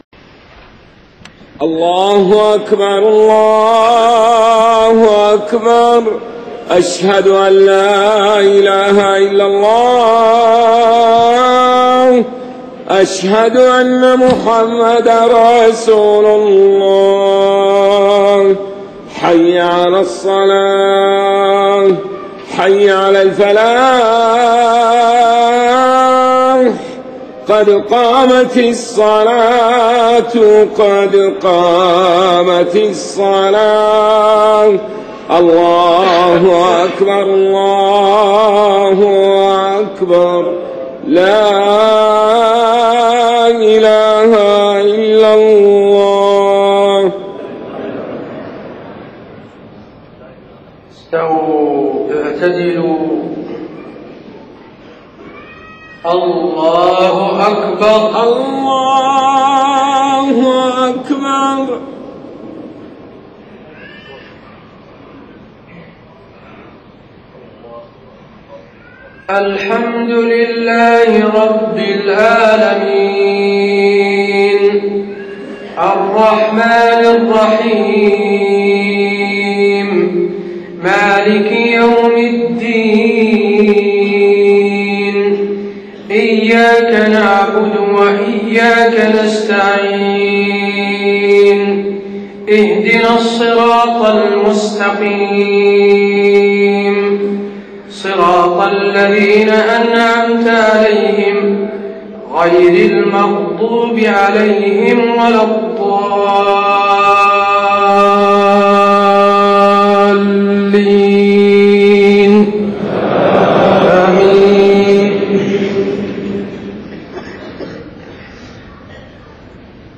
صلاة المغرب 19 محرم 1430هـ خواتيم سورة الفرقان 61-77 > 1430 🕌 > الفروض - تلاوات الحرمين